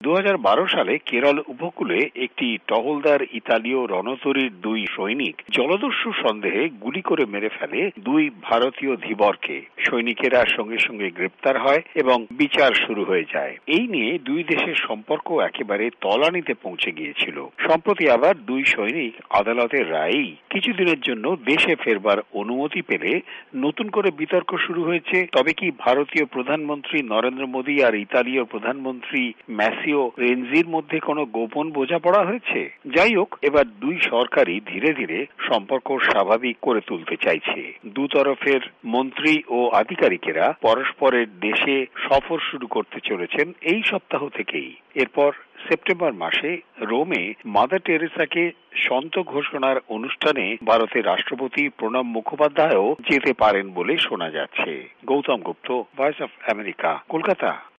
প্রতিবদন